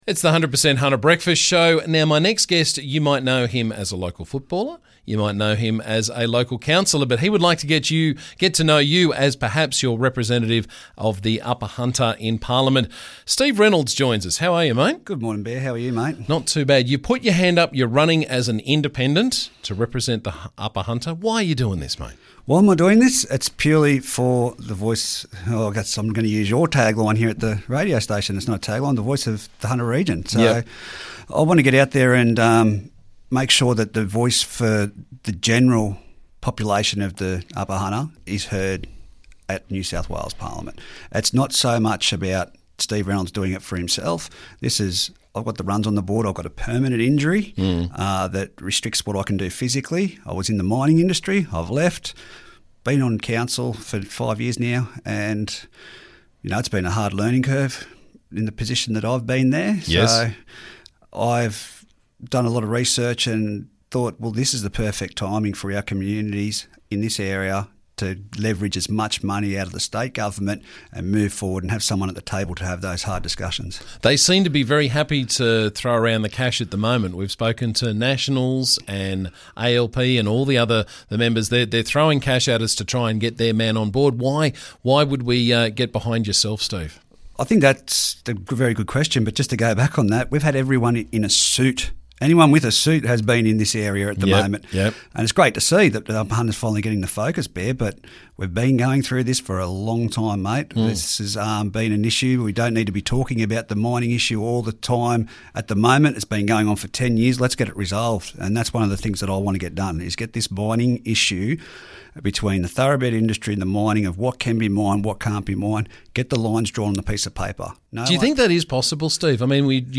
Muswellbrook Councillor Steve Reynolds is running as an Independent candidate for the upcoming By-Election and he was on the show this morning to talk about why he wants to represent our community in state parliament.